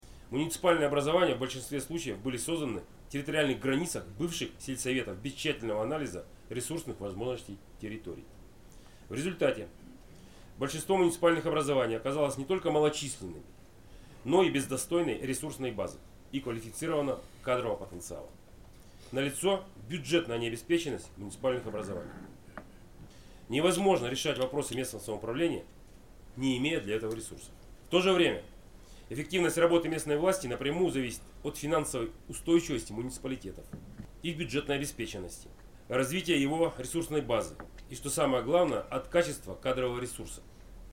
Об этом Олег Кувшинников заявил на расширенном заседании Правления Совета муниципальных образований области.
Олег Кувшинников рассказывает о нехватке ресурсов и кадров в муниципал